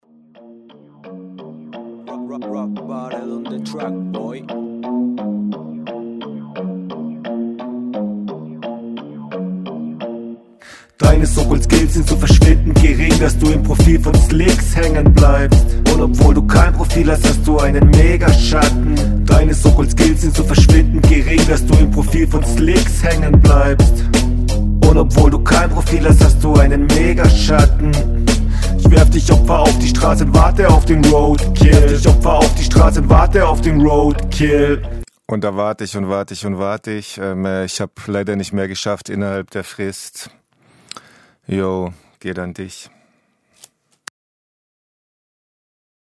Hook ist offbeat . oh.. ok schade schadeschadeschadeschadeschadeschadeschadeschadeschadeschadeschadeschade